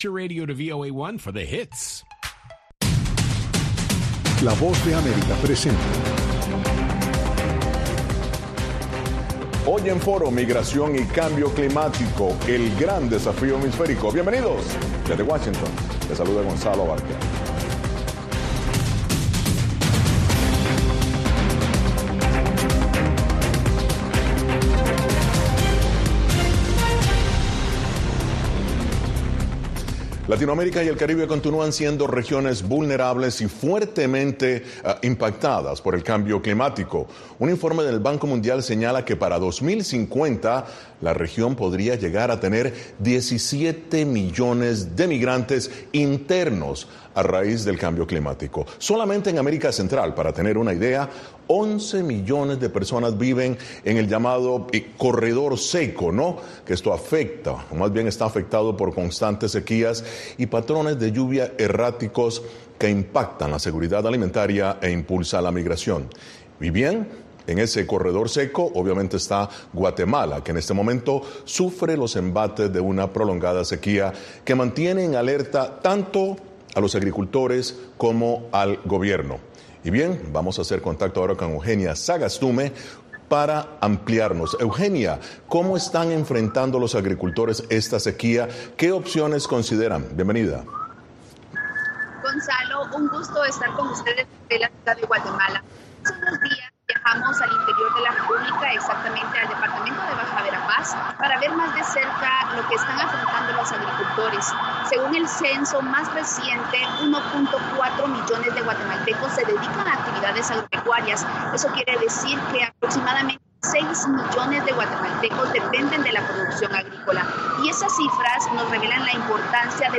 El punto de encuentro para analizar y debatir, junto a expertos, los temas de la semana.